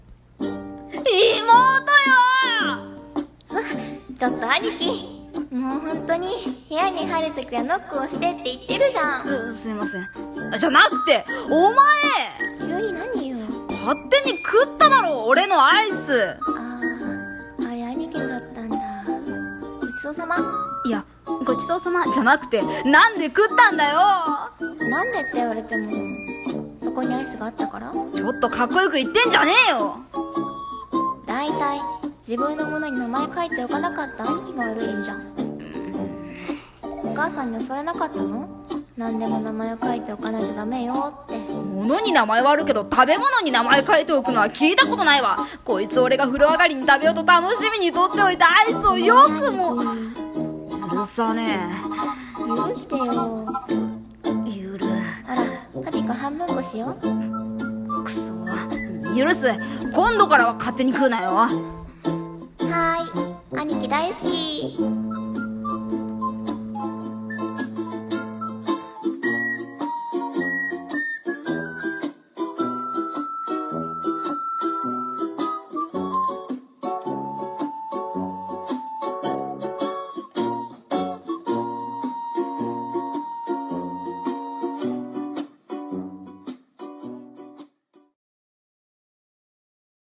【コラボ声劇どうぞ】兄妹喧嘩 その2